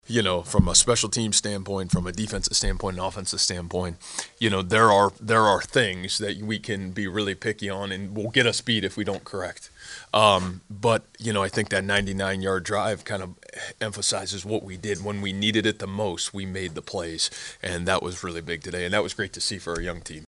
That’s ISU coach Matt Campbell.